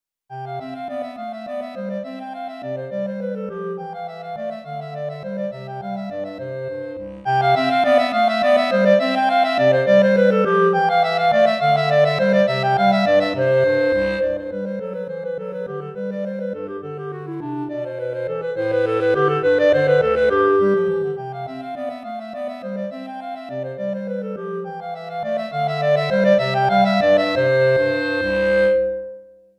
Clarinette en Sib et Clarinette Basse